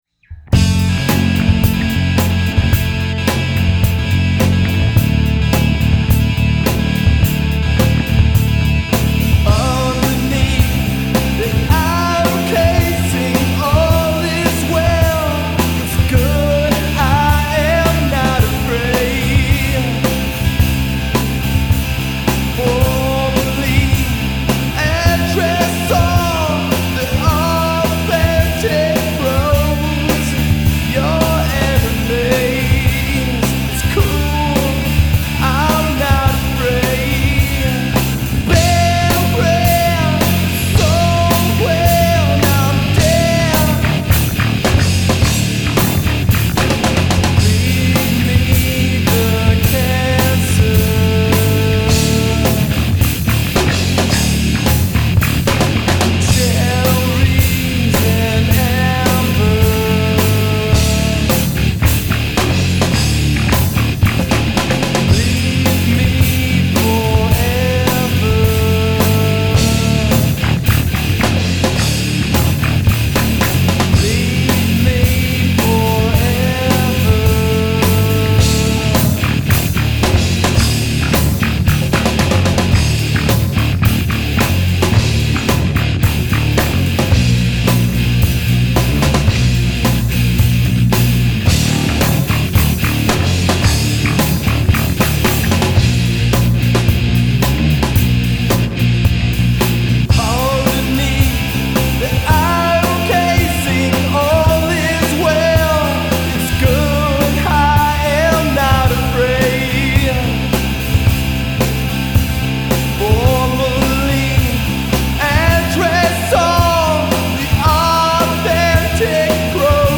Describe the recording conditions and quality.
Sennheiser MKH Neumann U87 Neumann TLM193 Electrovoice RE20 Neumann KM84 Coles 4038 Avid ProTools HD Millennia M-2b Avalon 747 DPA 4006 Shure SM57